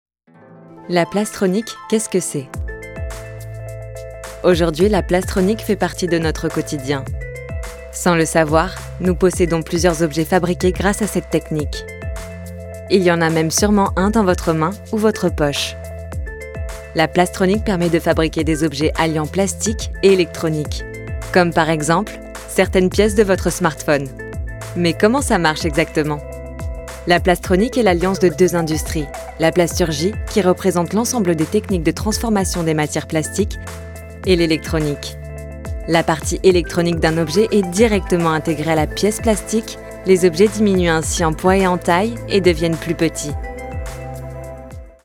Naturelle, Distinctive, Polyvalente, Fiable, Chaude
Vidéo explicative